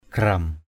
/ɡ͡ɣram/